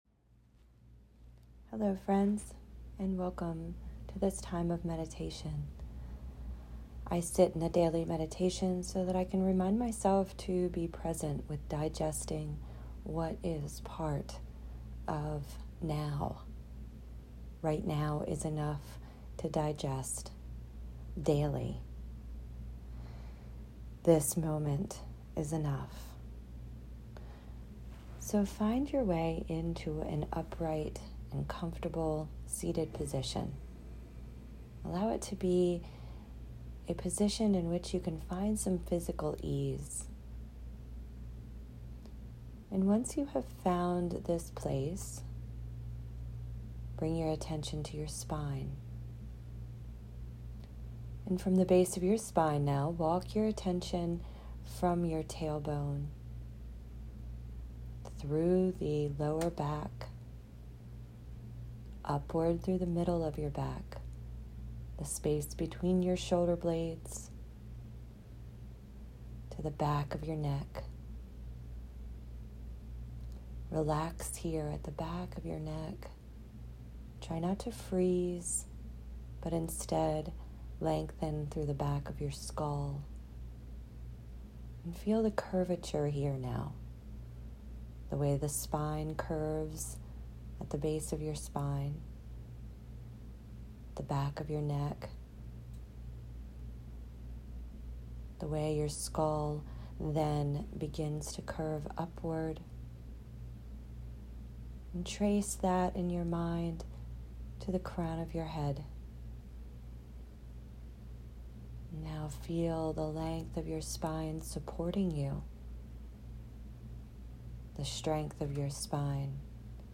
Serving All Beings Meditation
Serving-All-Beings-Meditation.m4a